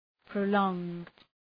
Προφορά
{prə’lɔ:ŋd}